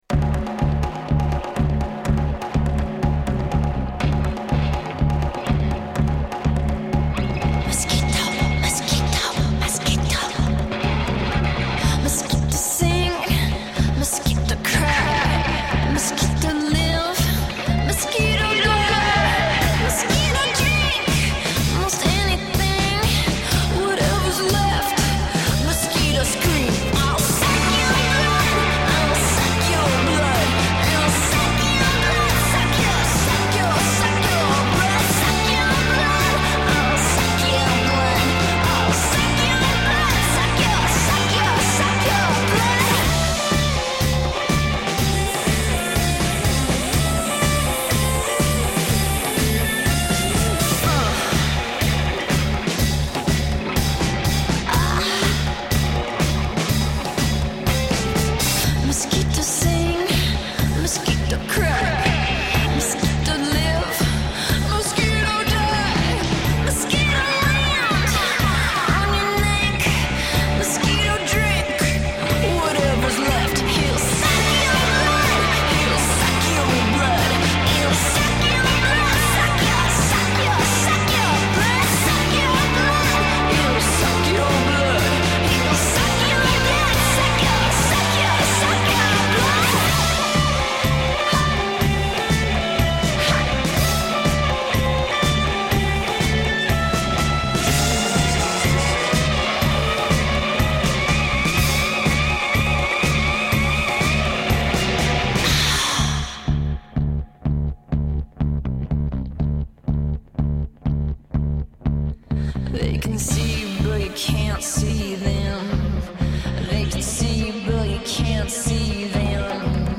The groove starts to get in on the title track.
She sings it like she’s sucked blood before.